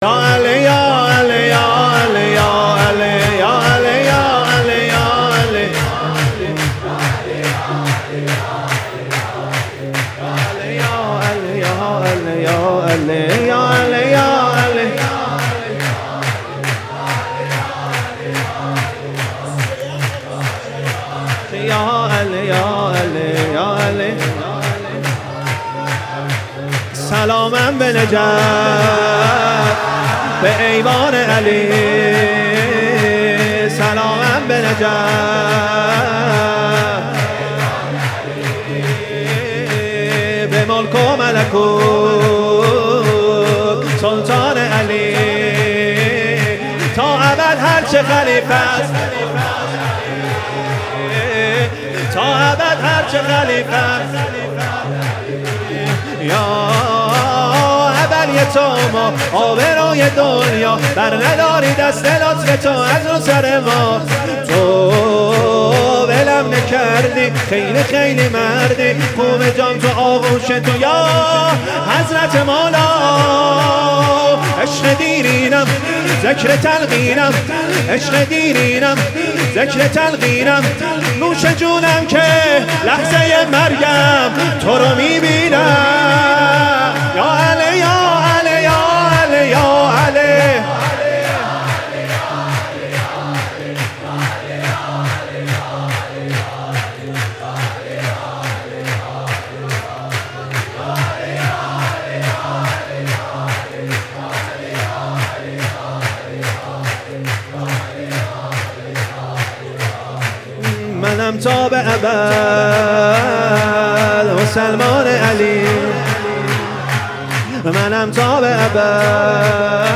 سرود | سلامم به نجف
ولادت حضرت زهرا(س)